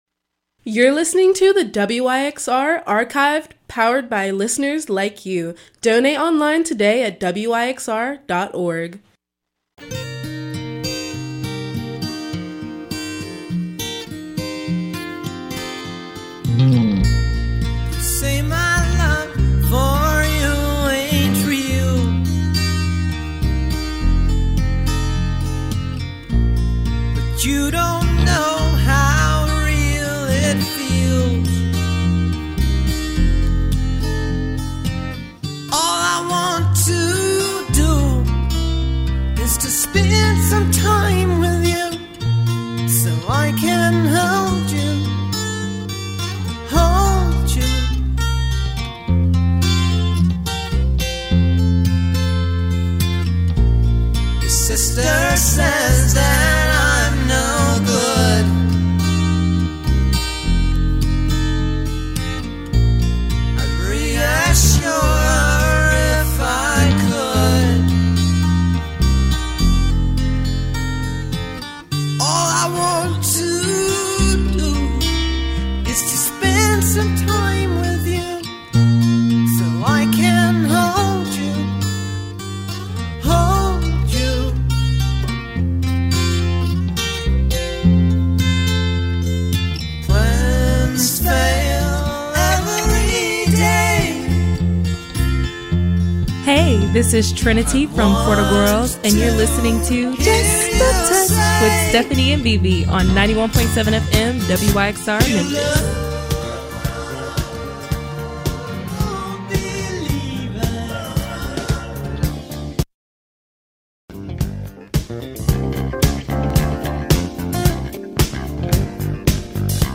Rhythm and Blues Soul Neo Soul